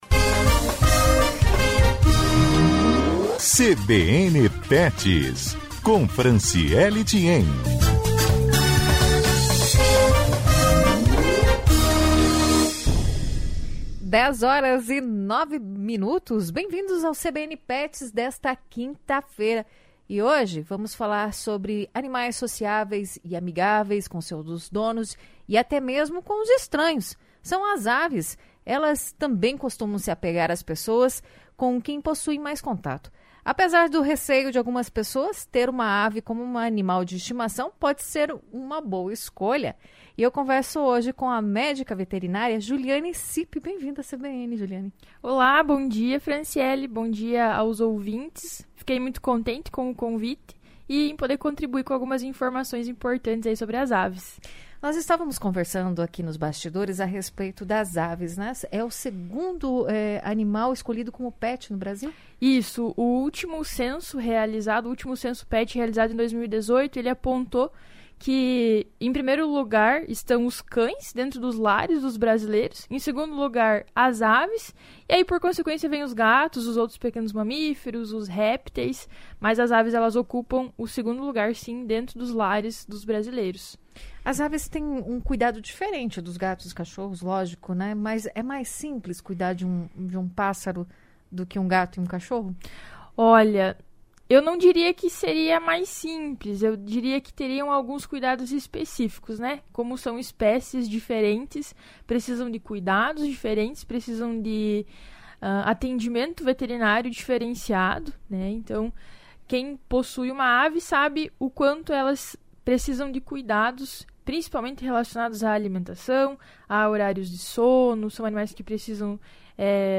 Confira a entrevista com a médica veterinária